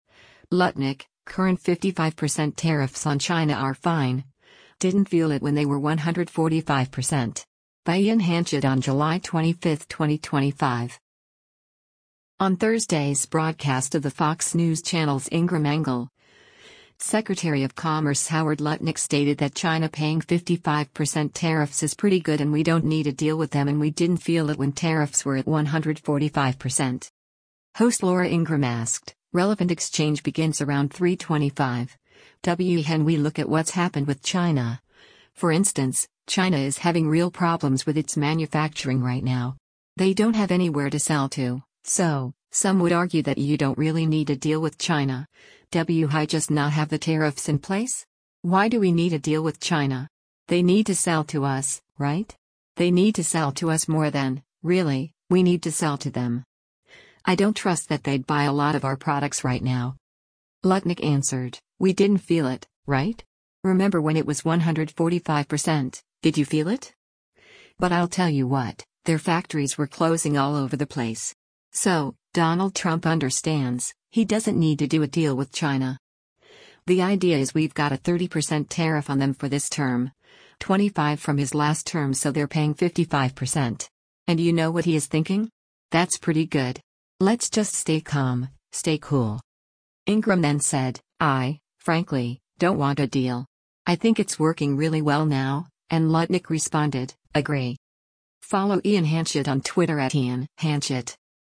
On Thursday’s broadcast of the Fox News Channel’s “Ingraham Angle,” Secretary of Commerce Howard Lutnick stated that China paying 55% tariffs is “pretty good” and we don’t need a deal with them and “We didn’t feel it” when tariffs were at 145%.